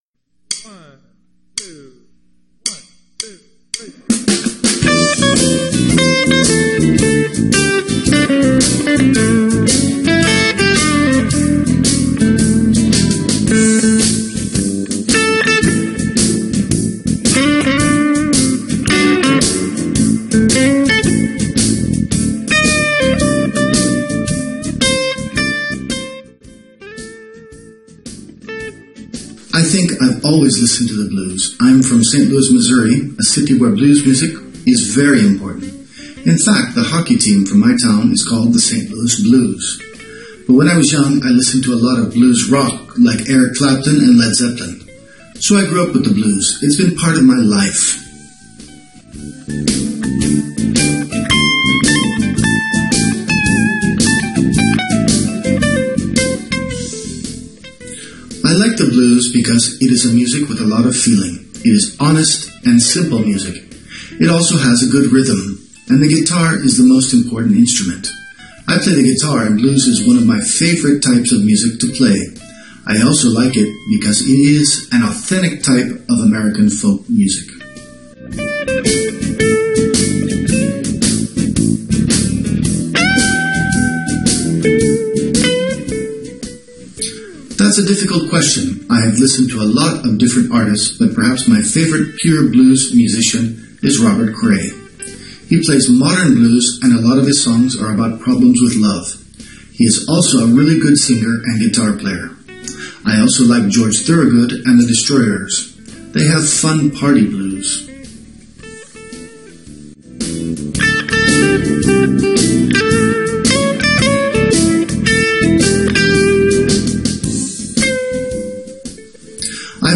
THEBLUES-THEINTERVIEW.mp3